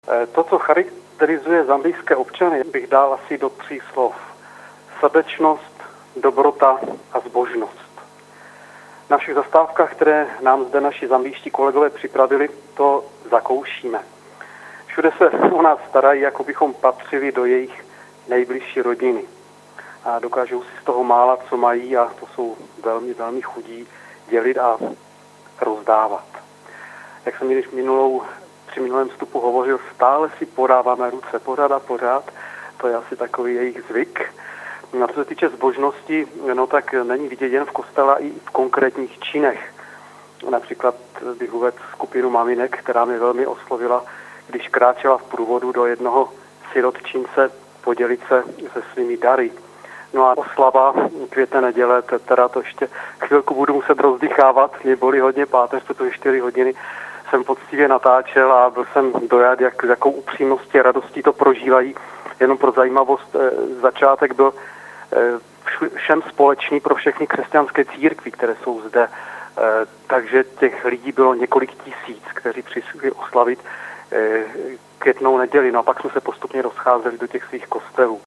Posluchačům Proglasu se podruhé telefonicky přihlásili o Květné neděli před 14. hod. zdejšího i zambijského času.